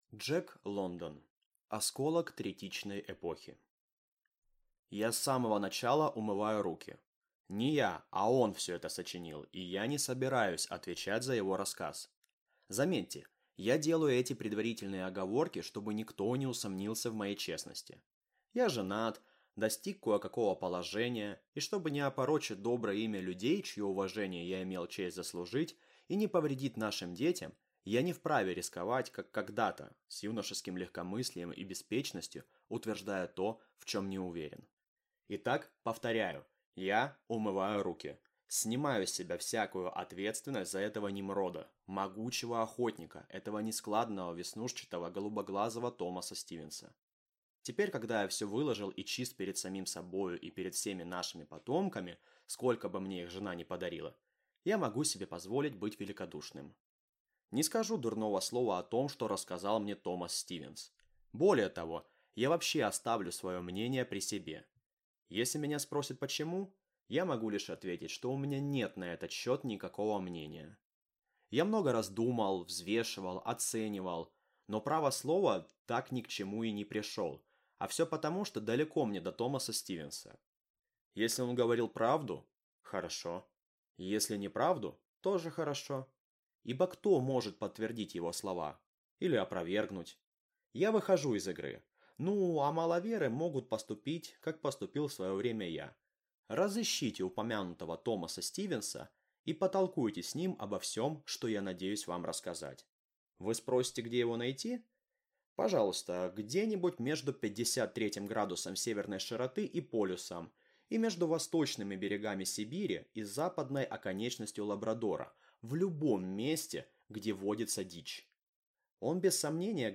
Аудиокнига Осколок третичной эпохи | Библиотека аудиокниг